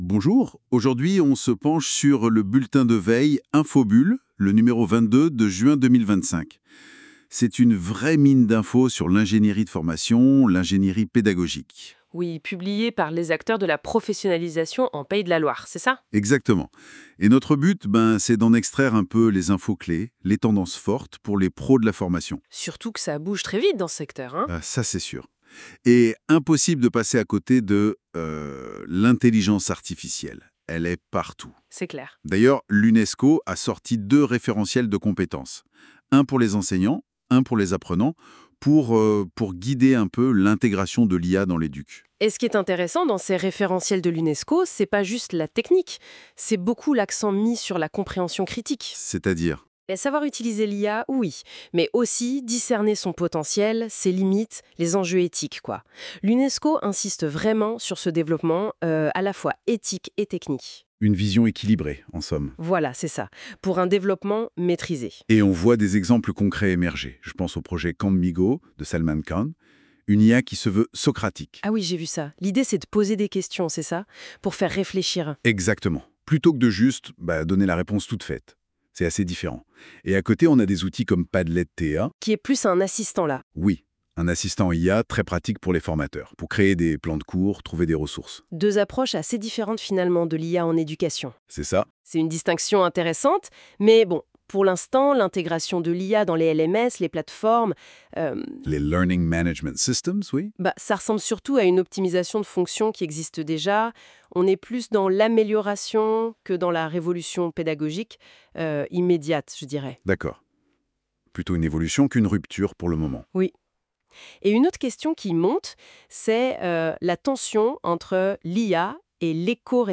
Le bulletin Infobulle n°22 – juin 2025 a été adapté en version audio grâce à une intelligence artificielle (NotebookLM).
🔍 Quelques légères incohérences peuvent subsister dans le style ou l’enchaînement, liées à l’interprétation automatique du contenu PDF.